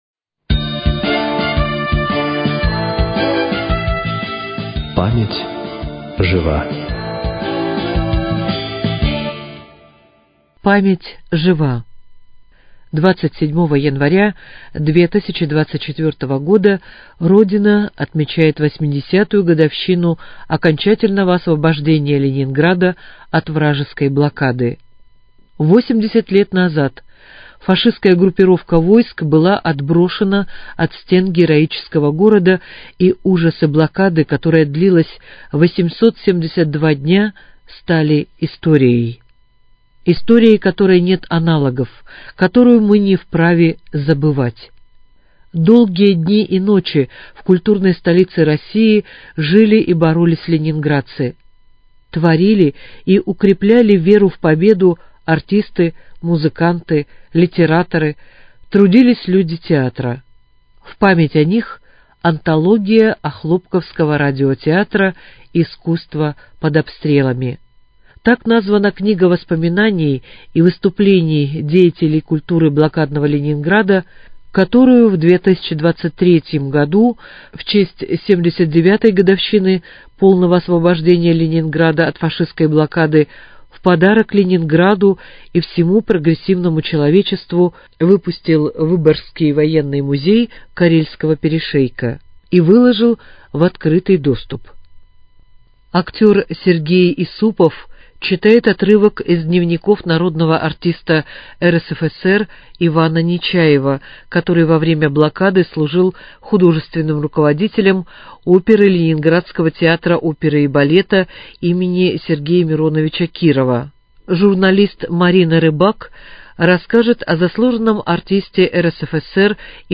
Память жива: Искусство под обстрелом. Читает